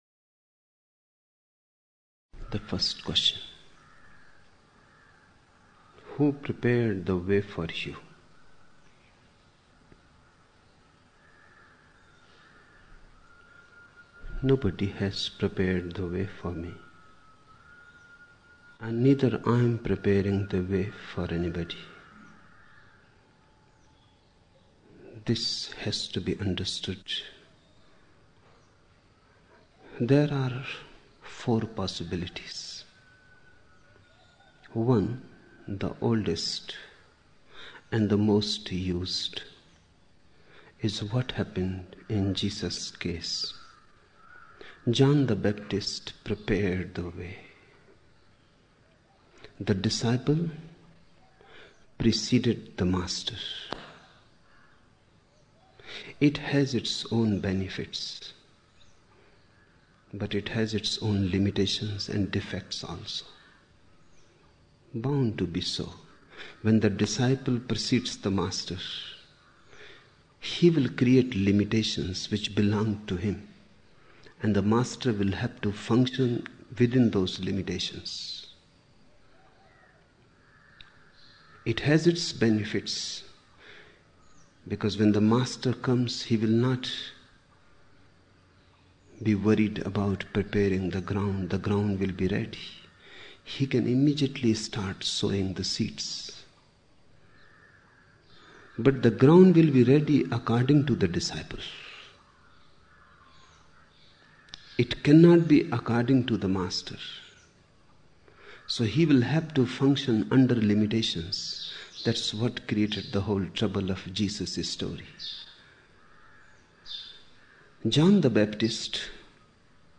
22 October 1975 morning in Buddha Hall, Poona, India